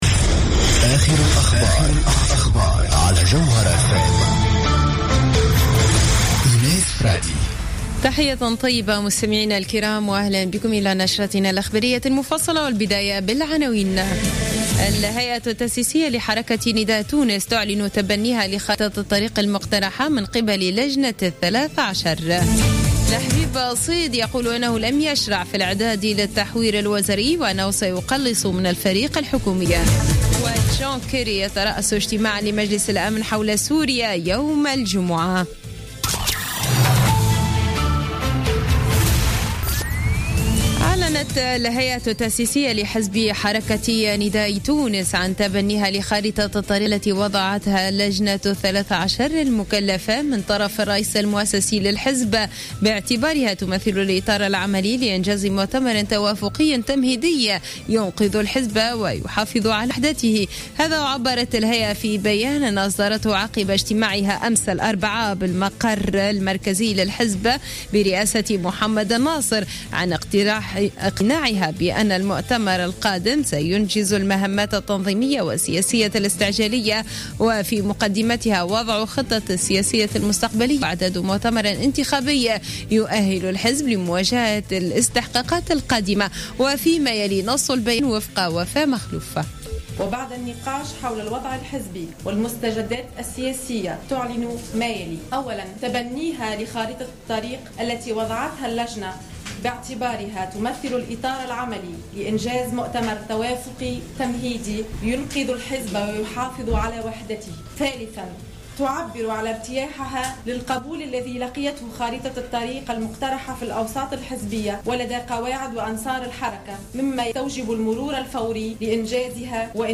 نشرة أخبار منتصف الليل ليوم الخميس 17 ديسمبر 2015